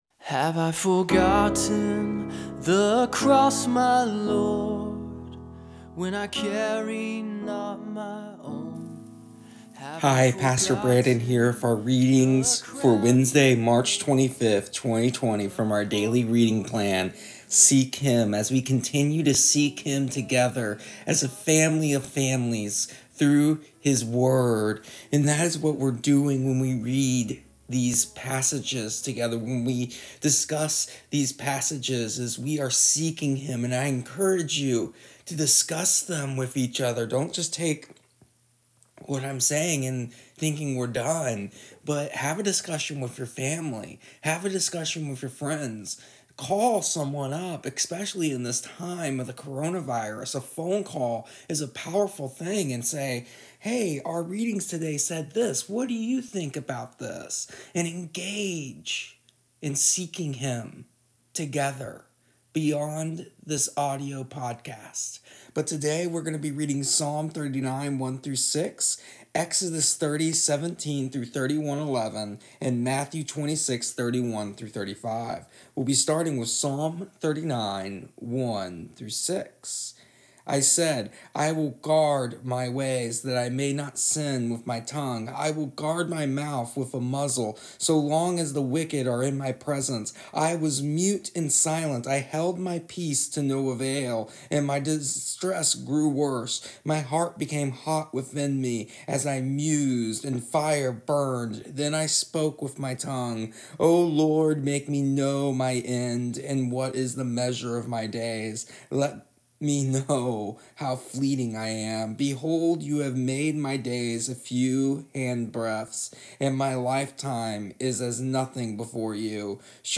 Today in our audio readings and short devotional we discuss how God has equipped each and every one of us as God needs to see to His will.